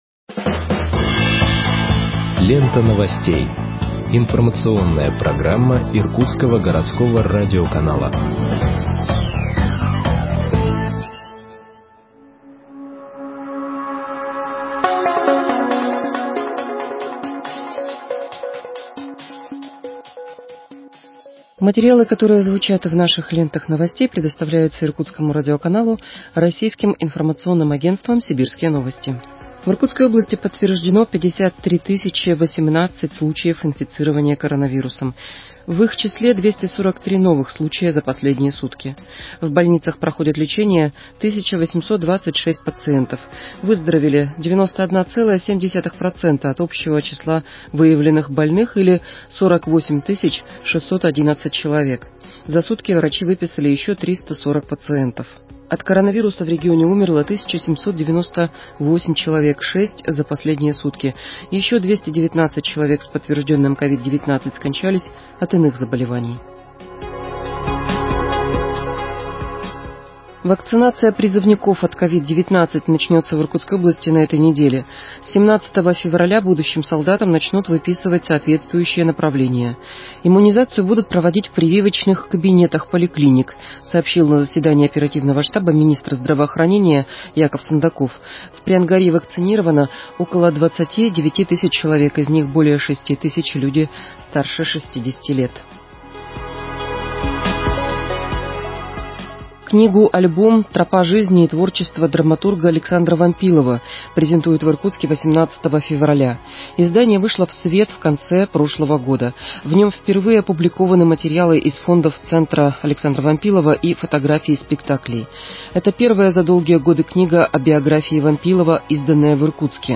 Выпуск новостей в подкастах газеты Иркутск от 16.02.2021 № 2